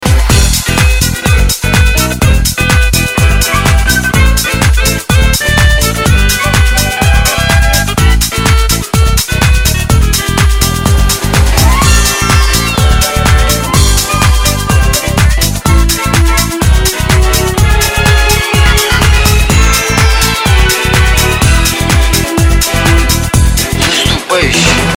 • Качество: 320, Stereo
позитивные
мужской голос
новогодние